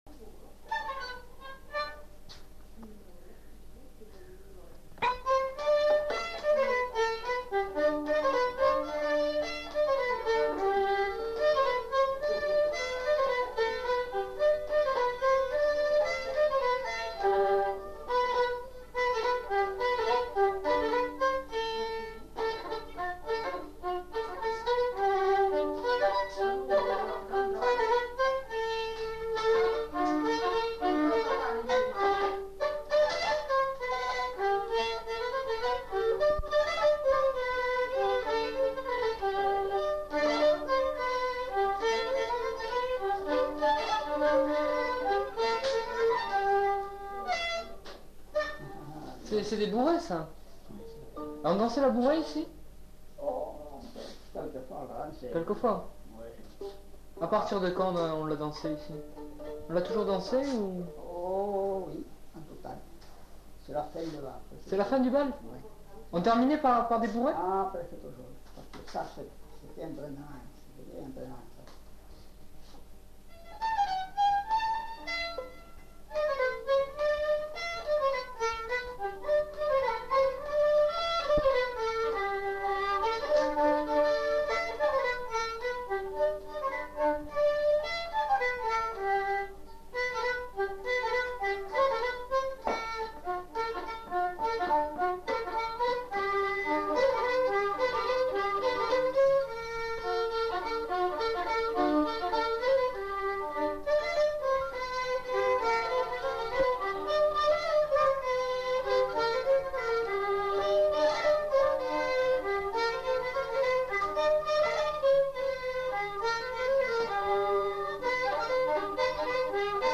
Lieu : Saint-Michel-de-Castelnau
Genre : morceau instrumental
Instrument de musique : violon
Danse : bourrée
Notes consultables : Précisions sur la pratique de la bourrée en fin de séquence. Puis reprise de l'air à 2 violons. Enchaînement de deux thèmes.